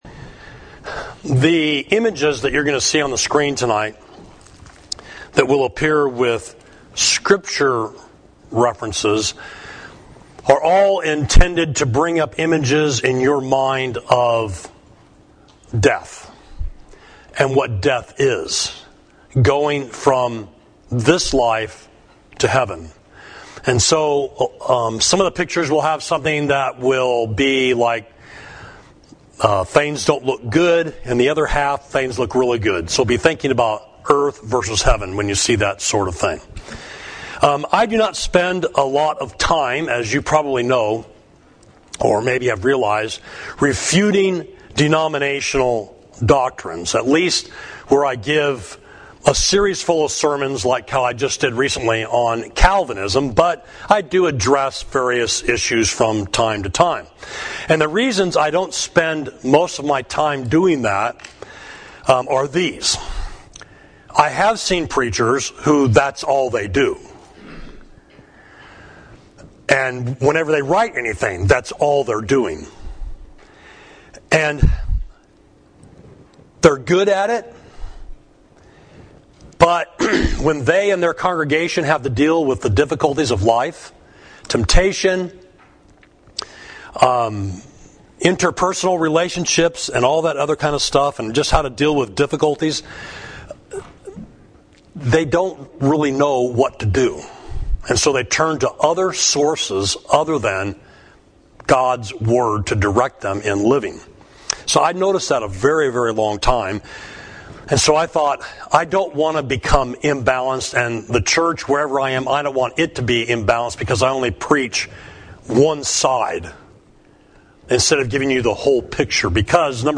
Sermon: Facing Death Fearlessly